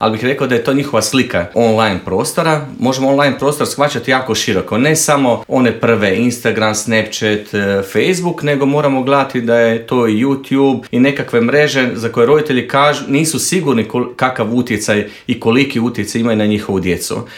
O svemu tome u Intervjuu Media servisa